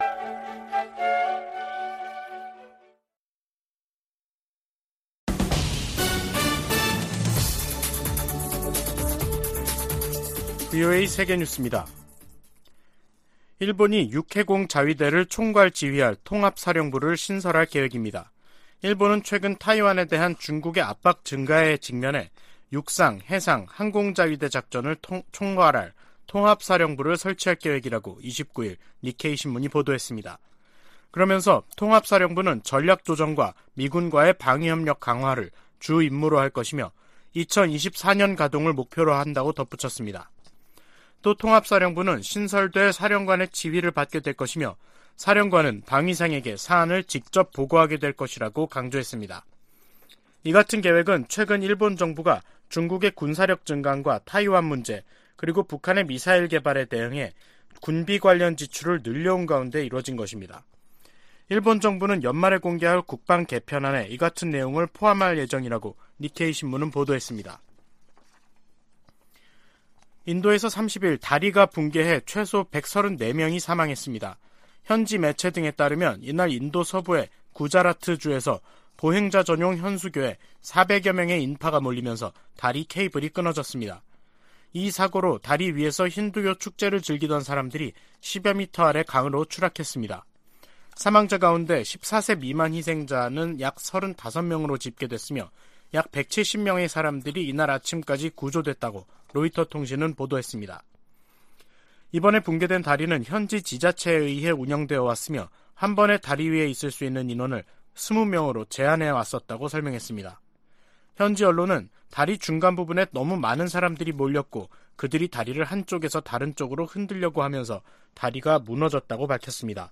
VOA 한국어 간판 뉴스 프로그램 '뉴스 투데이', 2022년 10월 31일 3부 방송입니다. 미국 국무부가 ‘한반도의 완전한 비핵화’에 대한 의지를 확인하면서 북한의 대화 복귀를 거듭 촉구했습니다. 미국과 한국의 최신 군용기들이 대거 참여한 가운데 한반도 상공에서 펼쳐지는 연합 공중훈련 비질런스 스톰이 31일 시작됐습니다.